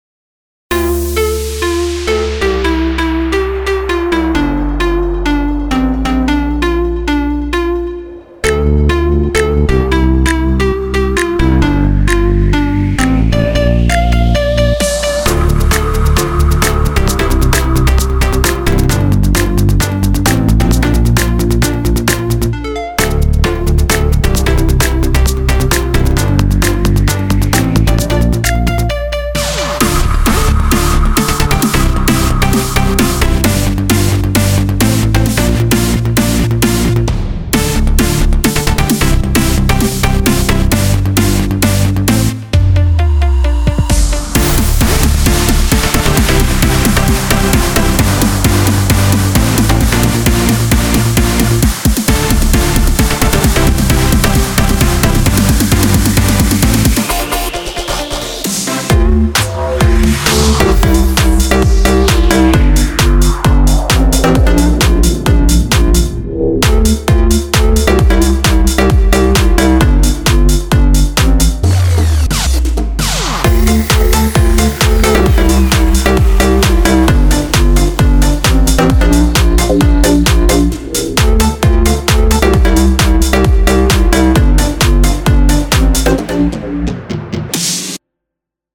טראק אורגן קורג
אתה עשית פה פיוצ’ר האוס
פגזזז אני הייתי עושה את הדרופ יותר בסיגנון ביג רום מלא ושמן כזה…(הסיגנון של הישנים של גאריקס.) אני חושב שזה היה הרבה יותר דוחף.
טוב מאוד, יש בדרופ קצת באלאגן, ועומס בתדרים הנמוכים, כדאי להנמיך טיפה את הבס…